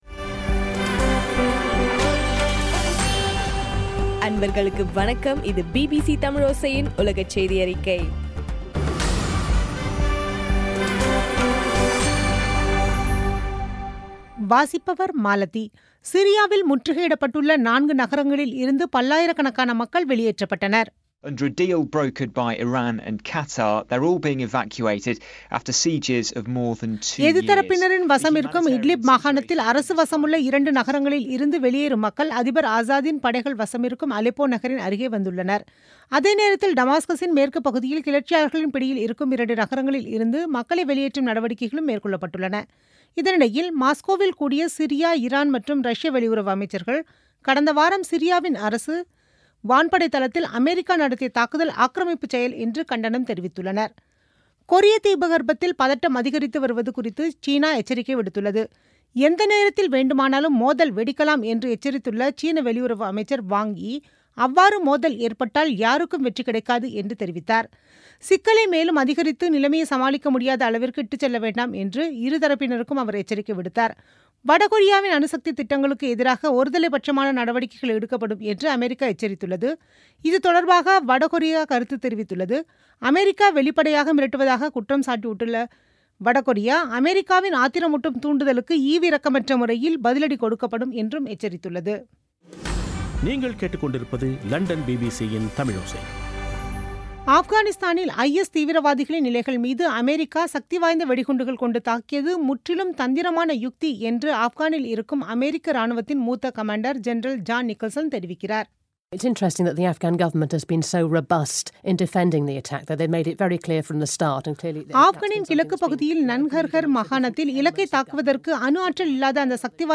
பிபிசி தமிழோசைசெய்தியறிக்கை (14/04/2017)